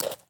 / mob / parrot / eat3.ogg
eat3.ogg